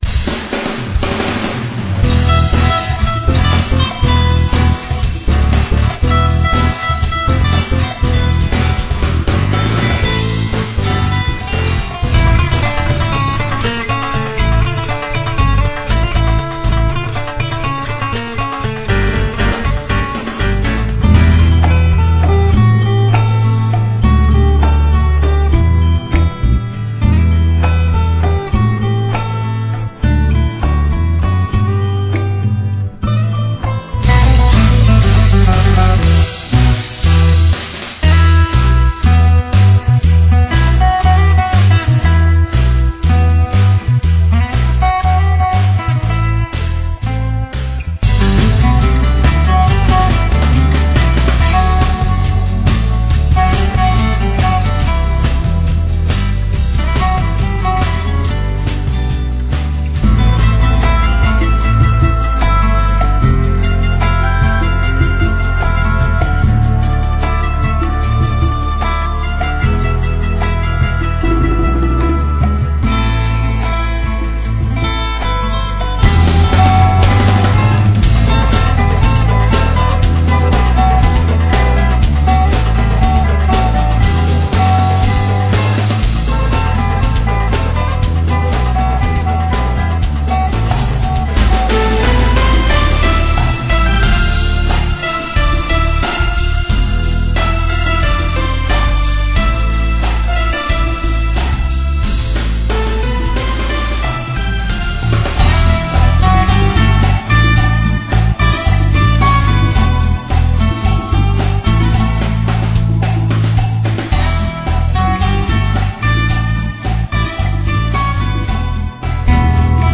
Rock &Acoustic instrumental music